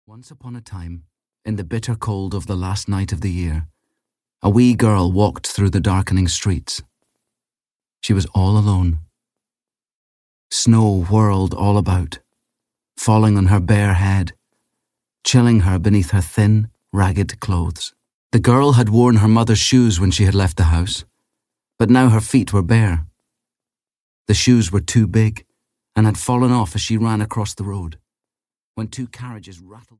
The Little Match Girl (EN) audiokniha
Ukázka z knihy
• InterpretEwan Mcgregor